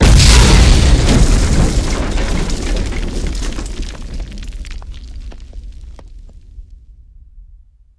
Âm thanh tiếng Nổ của thuốc nổ TNT